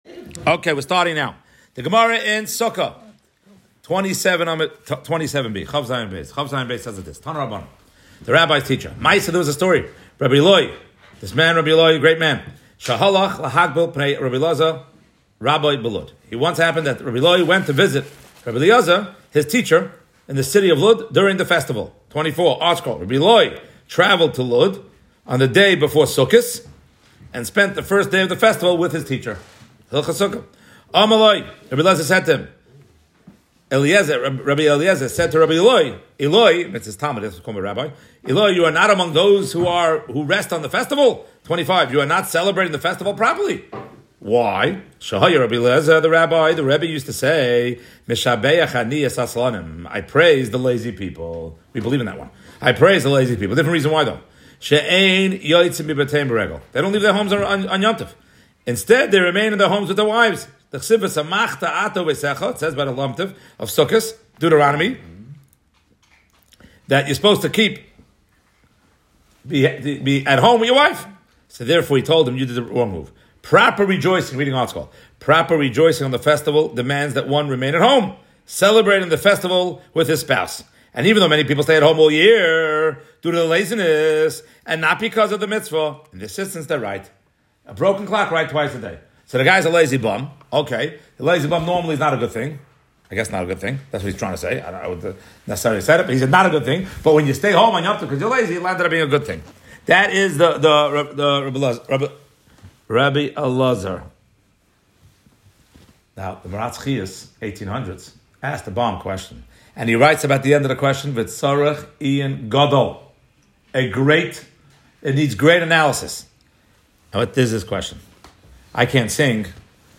Shiur